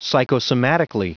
Prononciation du mot psychosomatically en anglais (fichier audio)
Prononciation du mot : psychosomatically